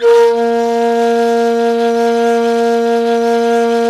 NYE FLUTE00L.wav